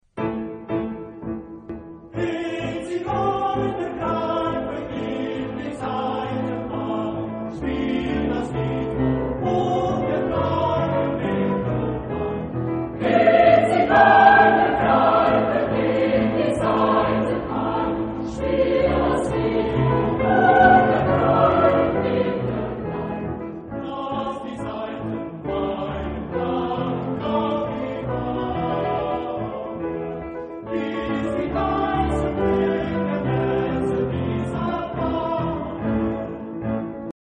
SATB (4 voices mixed).
Romantic. Lied.